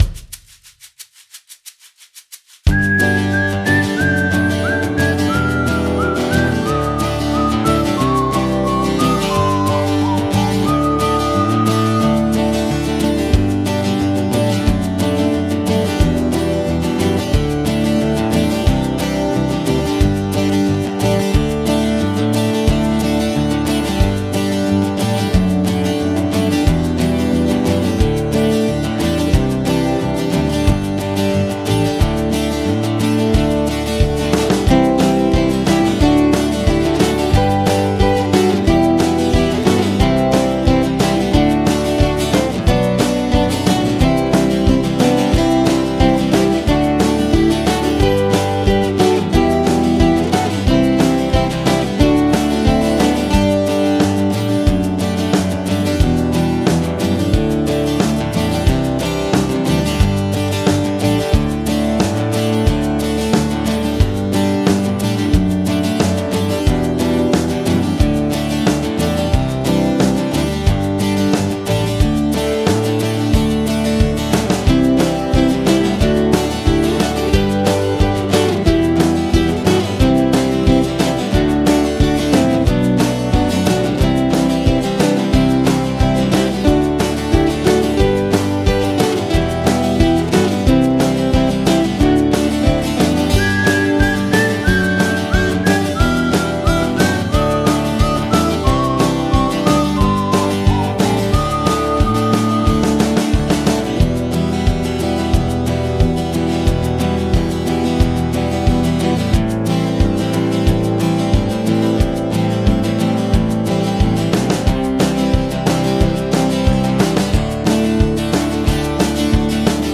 No Vocals Jam Track